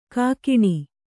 ♪ kākiṇi